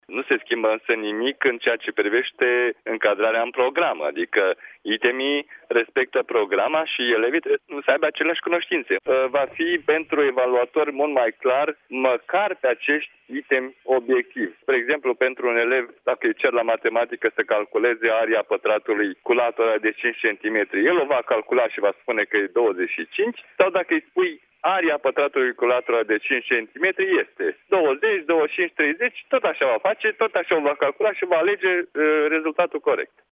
Președintele Centrului Naţional de Evaluare şi Examinare dă și un exemplu de cerință care ar putea apărea la viitoarele examene.